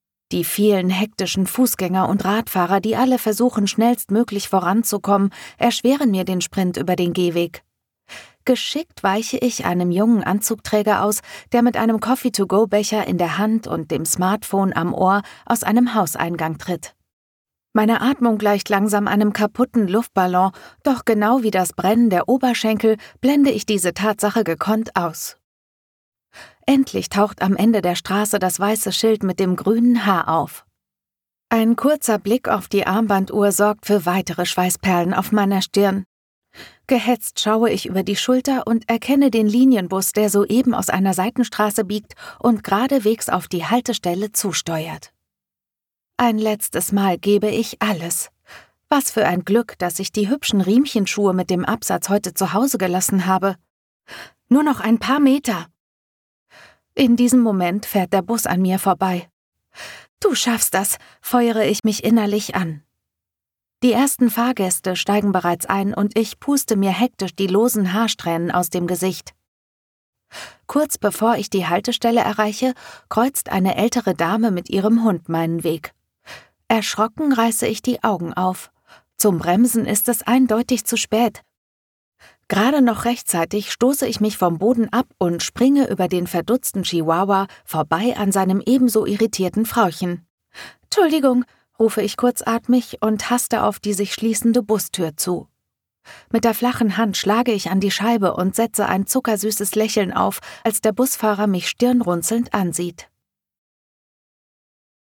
GERMAN VOICE ARTIST FOR ADVERTISINg, audiobooks, RADIO PLAYS, documentaries and E-Learning Every voice is unique.Every voice has its own nuances and facets, it is a reflection of a person's soul and character.Sometimes, a voice can be warm and inviting, other times, it might be rough and earthy, like 120-grit sandpaper.
My voiceovers are delivered in flawless, error-free German.